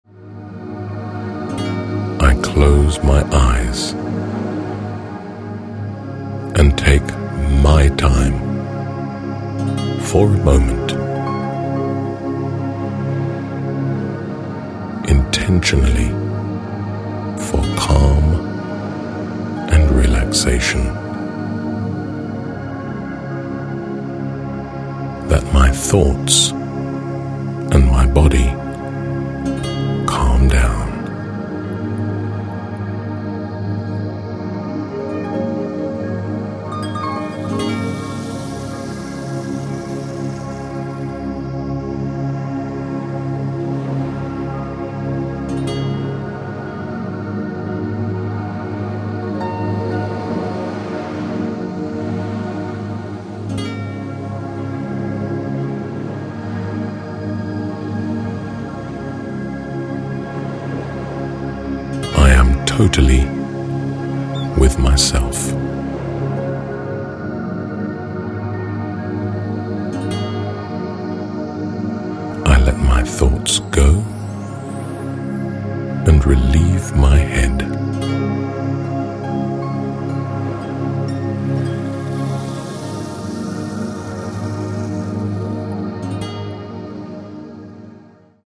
The sounds and music are carefully put together based on the latest research in sound design for relaxation purposes. They take full effect in a soothing and stimulating way. The pitch is set to the natural standard A of 432 Hz.
burnout_and_fatigue_syndrom_help_reload_in_15_minutes_focus_on_calm.mp3